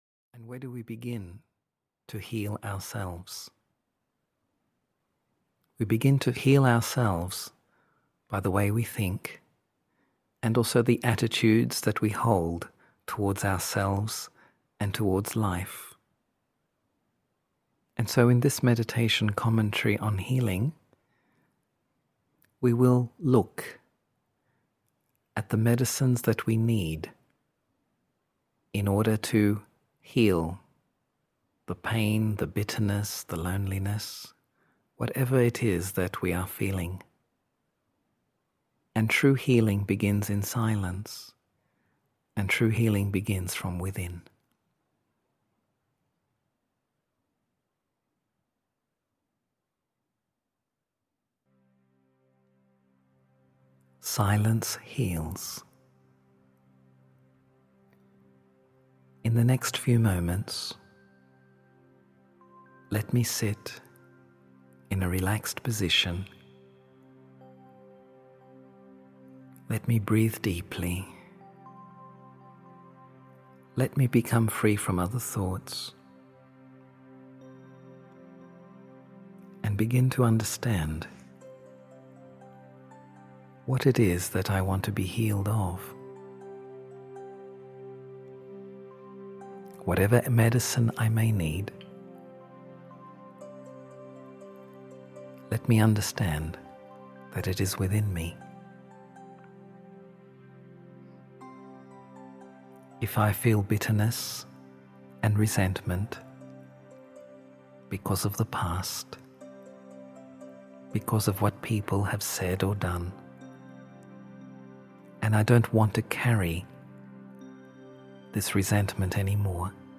A Time for Healing (EN) audiokniha
Ukázka z knihy